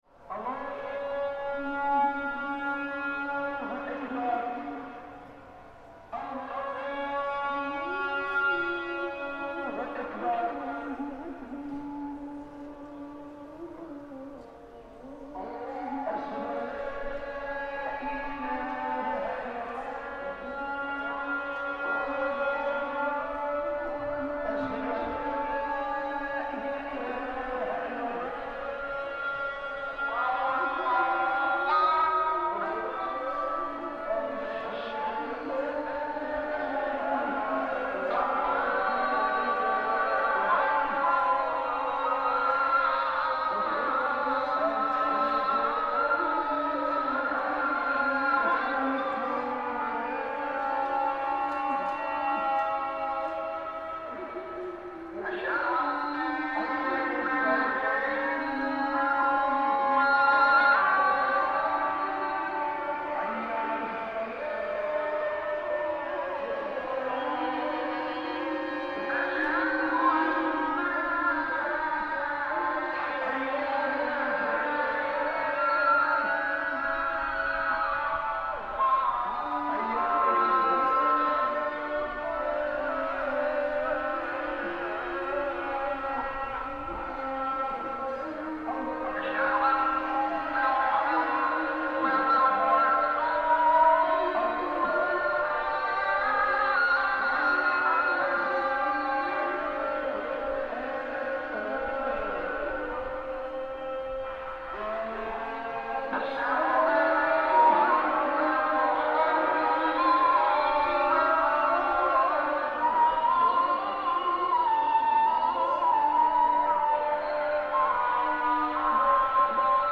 Isha call to prayer in Nabeul 4:30
This recording captures the "Isha" call to prayer in the medina of Nabeul, Tunisia. "Isha" is the night prayer, and is announced after dark. Nabeul has two main mosques in the medina, plus other call to prayers can be heard in the distance. The presence of space is well recognisable due to the various distances of the different Isha calls.